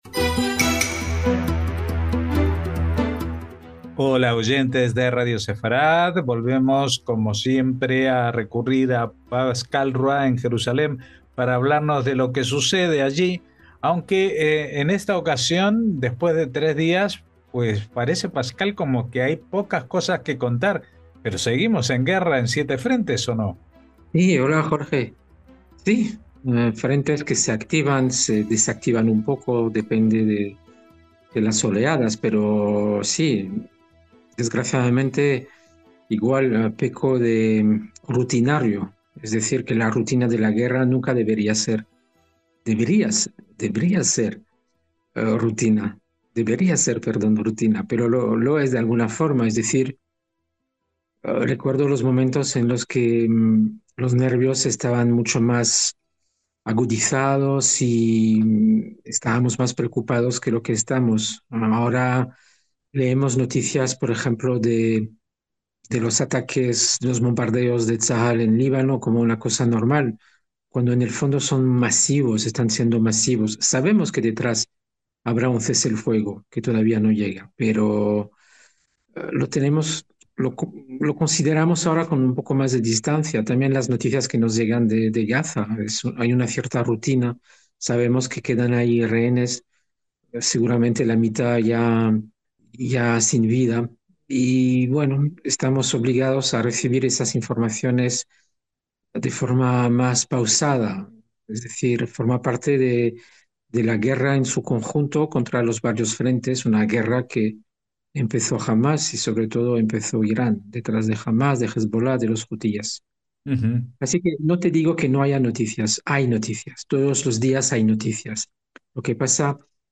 NOTICIAS CON COMENTARIO A DOS - Cuando la guerra se vuelve rutinaria, parece que no pasa gran cosa, pero es el futuro lo que está en juego día a día.